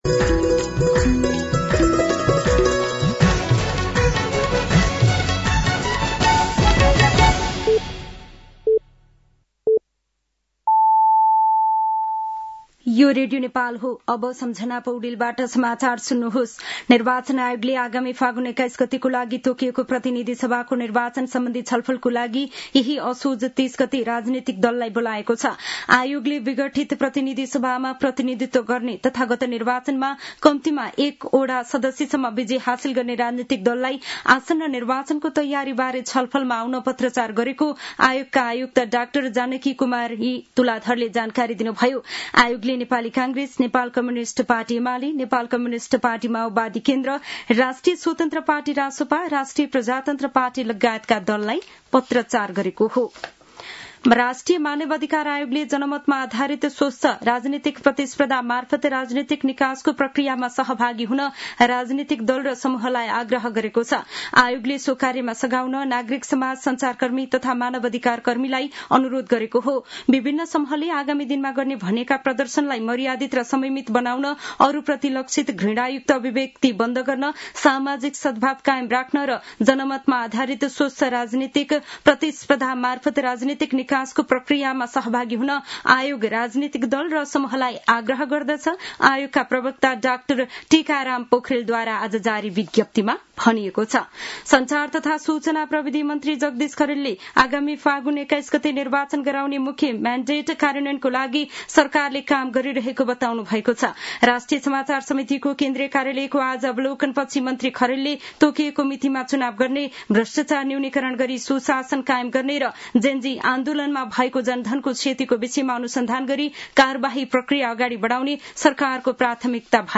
साँझ ५ बजेको नेपाली समाचार : २२ असोज , २०८२